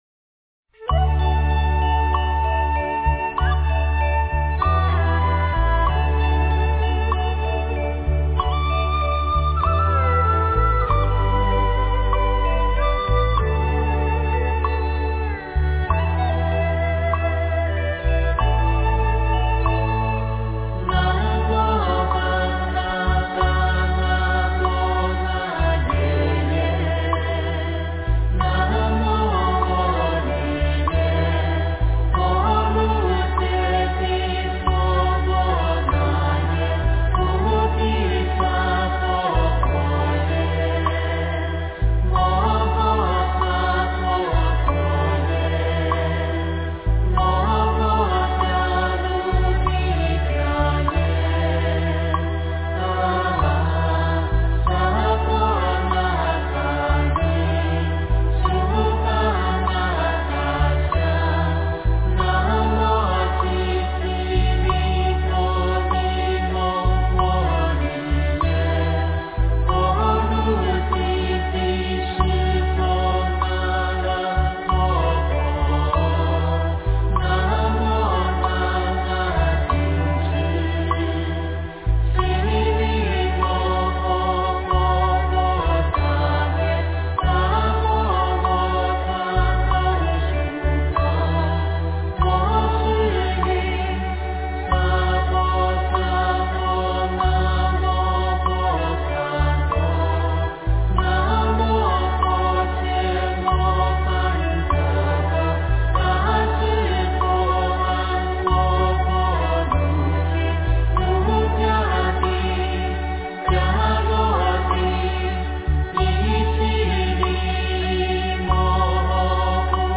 大悲咒 诵经 大悲咒--圆满自在组 点我： 标签: 佛音 诵经 佛教音乐 返回列表 上一篇： 大悲咒-汉音 下一篇： 大悲咒（藏音） 相关文章 观音行愿曲--觉慧儿童合唱团 观音行愿曲--觉慧儿童合唱团...